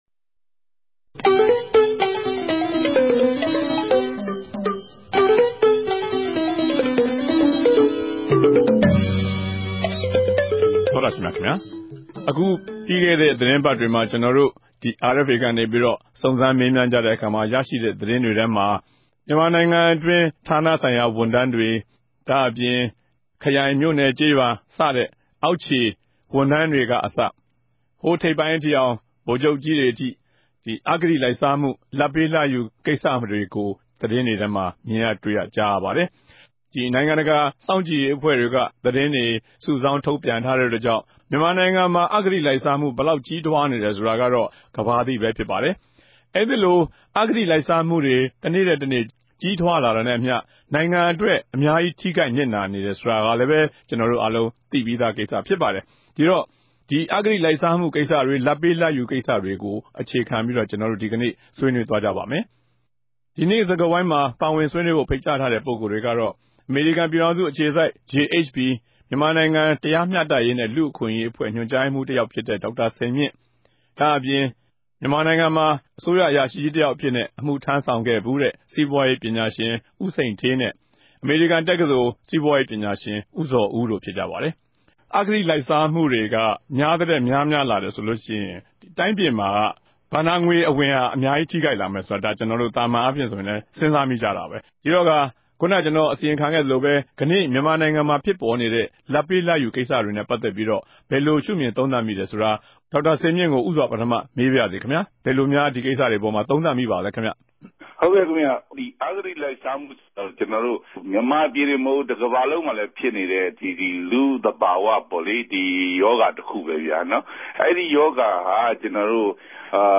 တနဂဿေိံြ စကားဝိုင်း။